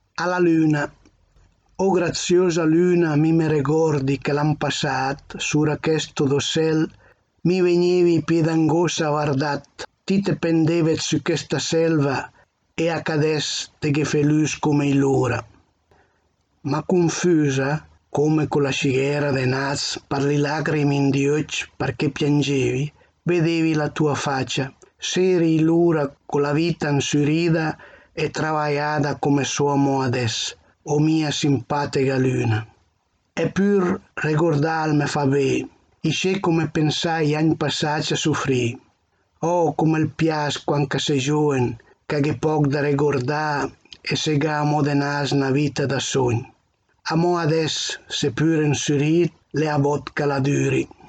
Ala lǜna | Dialetto di Albosaggia